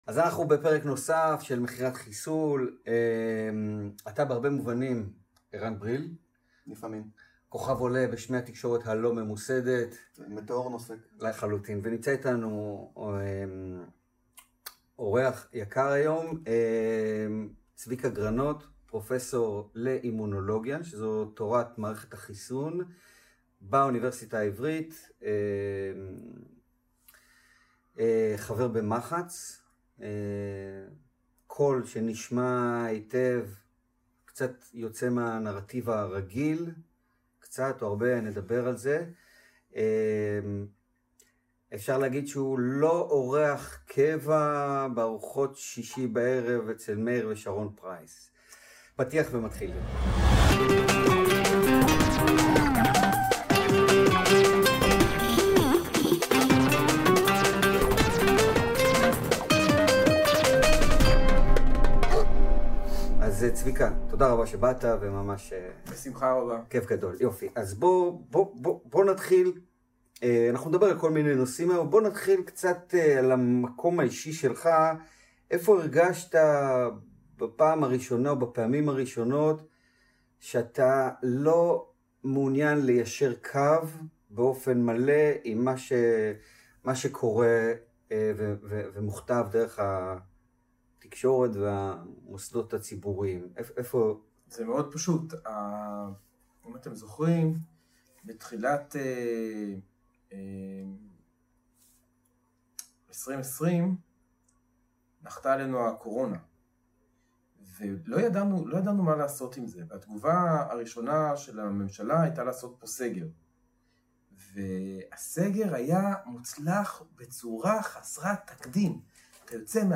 הראיון המלא.